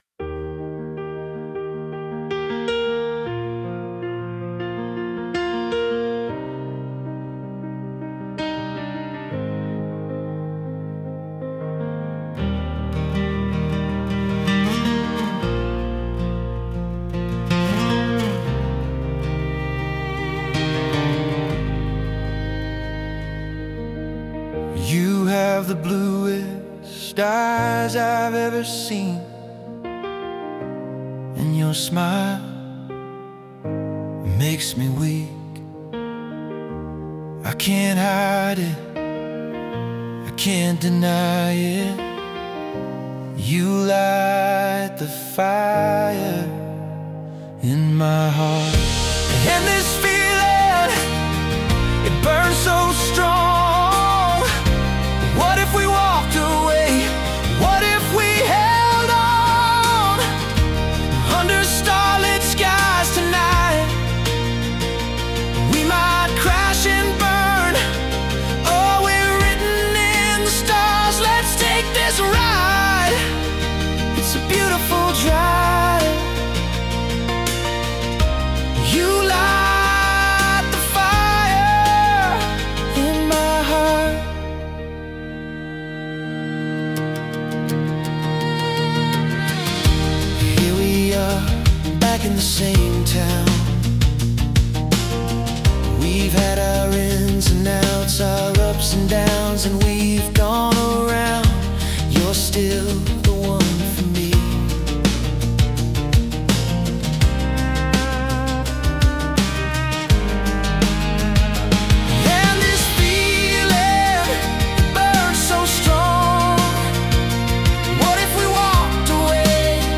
Approximate BPM: 120–128 BPM